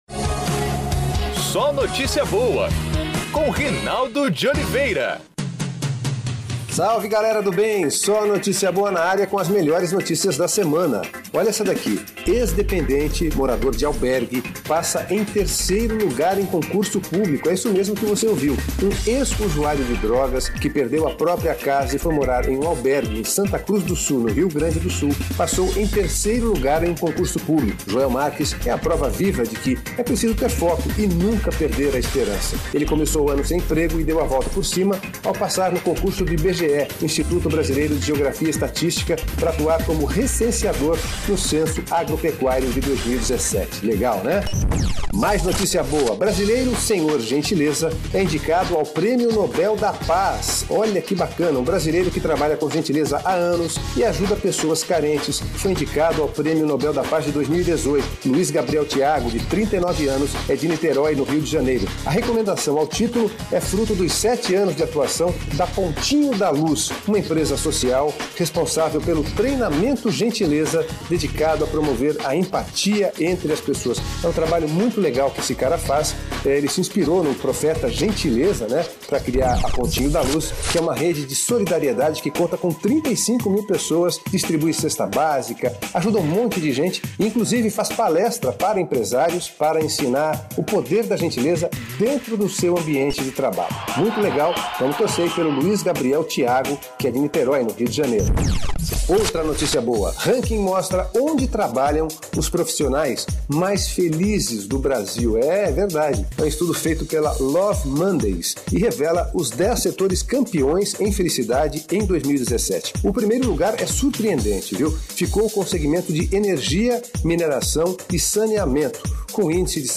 É o PodCast SóNotíciaBoa, exibido diariamente em pílulas na Rádio Federal, de Brasília.